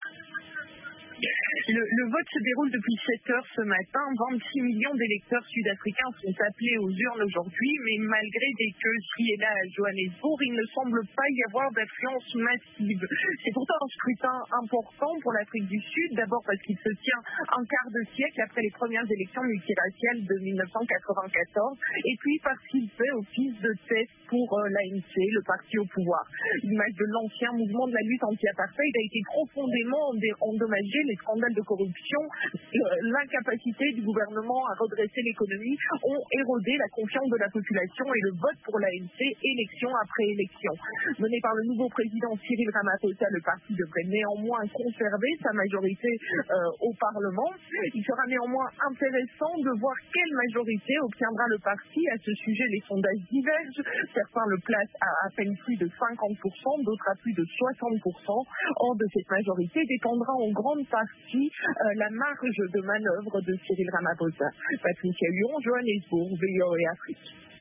Le point avec notre correspondante en direct de Johannesburg